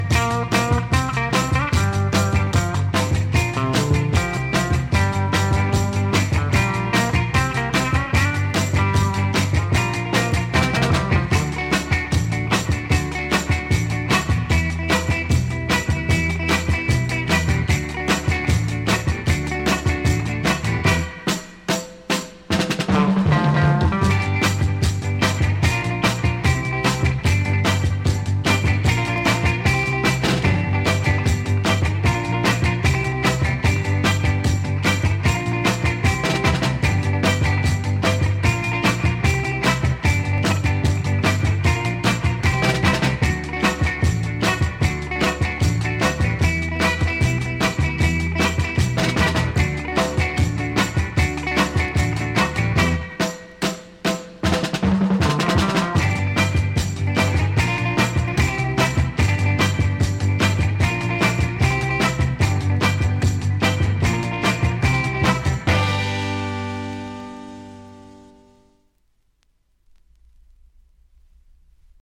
an instant party starter for sure